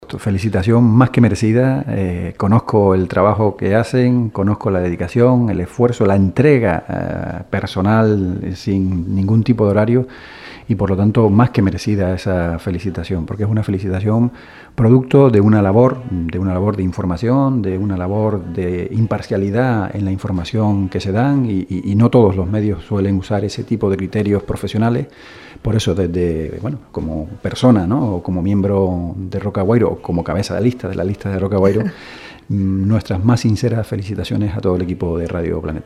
En estos días se ha dado a conocer su equipo de gobierno y las delegaciones que cada uno tendrá. De todo ello nos hablo en la mañana de hoy en los estudios de Radio Planeta Gran Canaria por motivo del 12 Aniversario